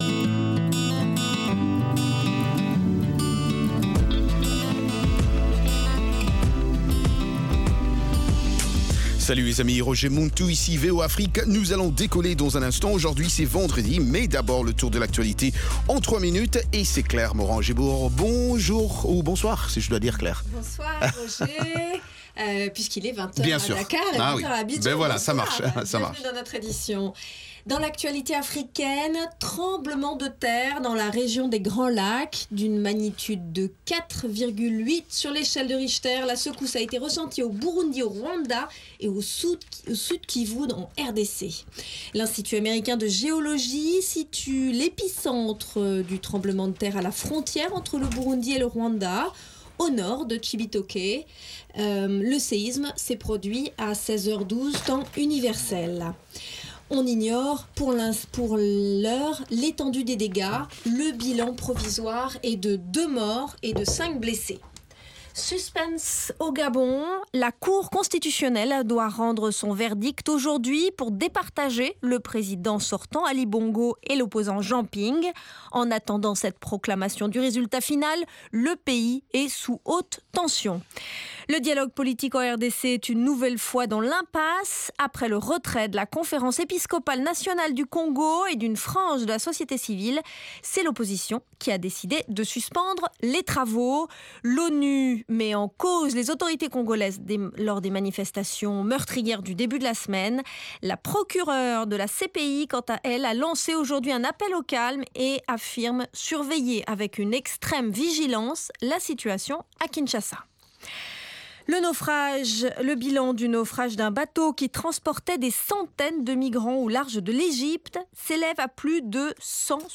Zouk, Reggae, Latino, Soca, Compas et Afro